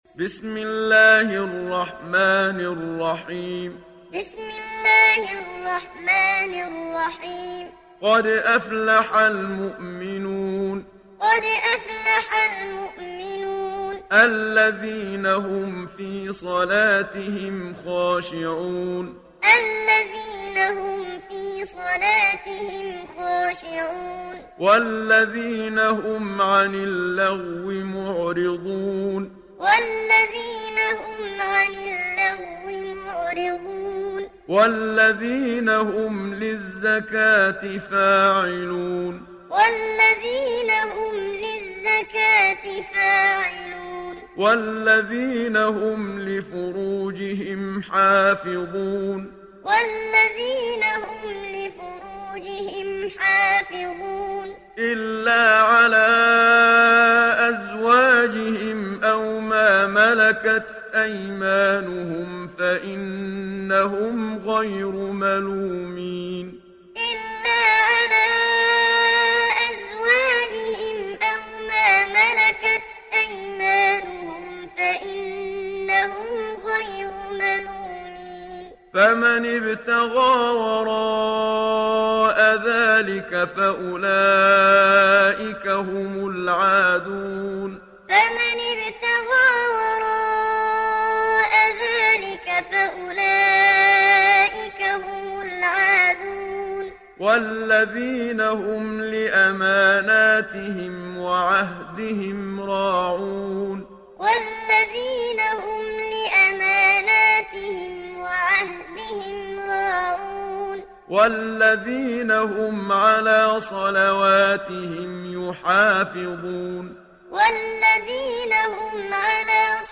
دانلود سوره المؤمنون محمد صديق المنشاوي معلم